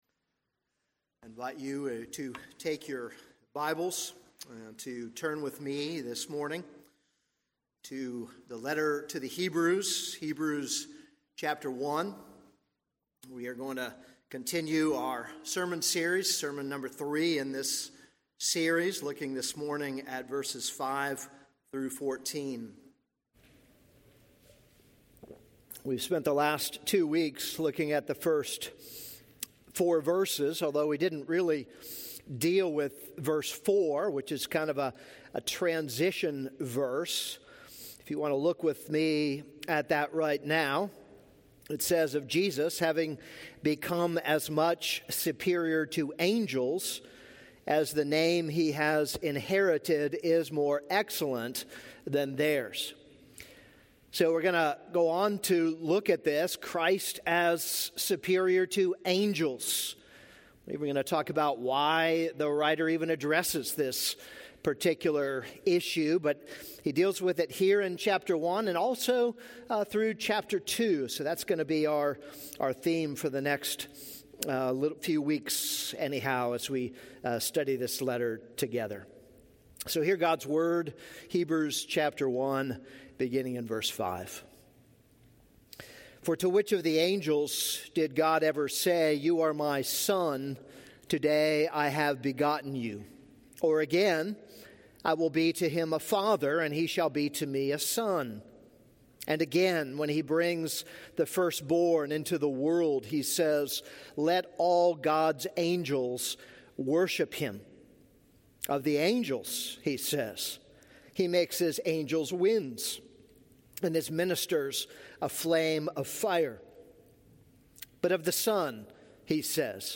This is a sermon on Hebrews 1:5-14.